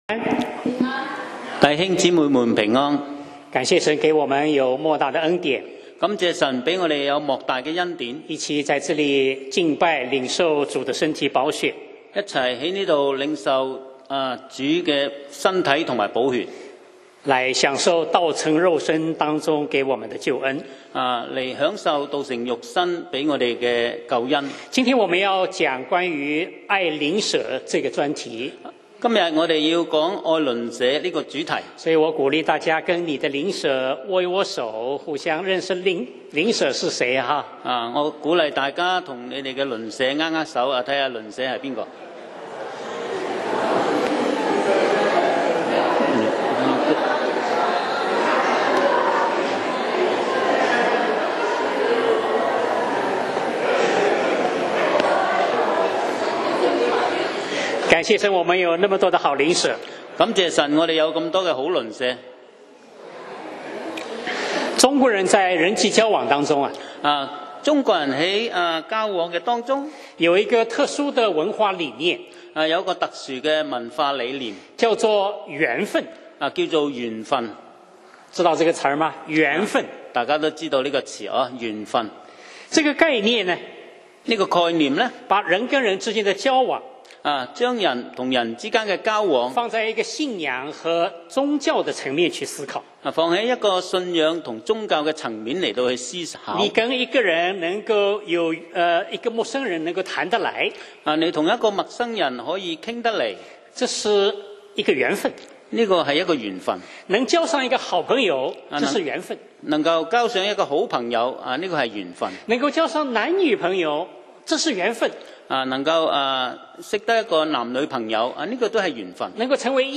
講道 Sermon 題目 Topic：與鄰舍分享你生命的禮物 經文 Verses：路加福音1：57-66. 57以利沙伯的产期到了，就生了一个儿子。